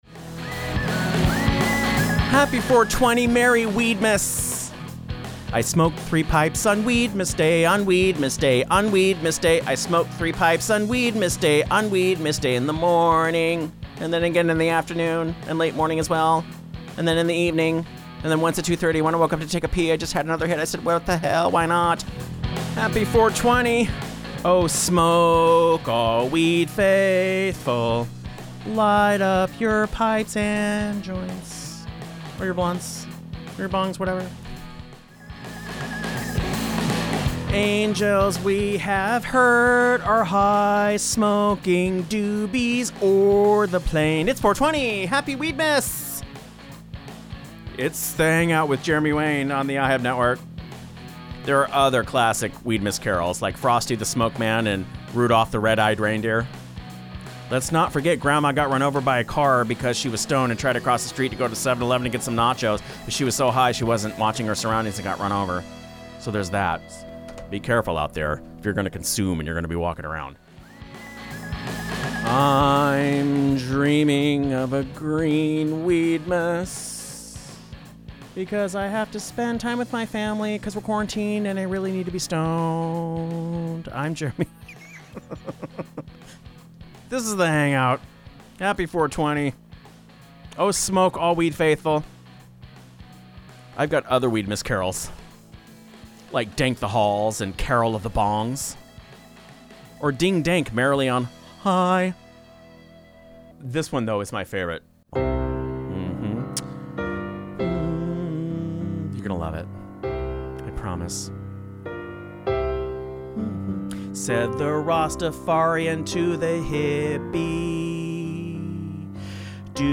It's a musical train wreck (and we're not just talking the cannabis strain either).